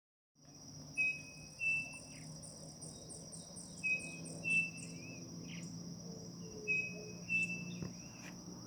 Striped Cuckoo (Tapera naevia)
Location or protected area: Concordia
Condition: Wild
Certainty: Recorded vocal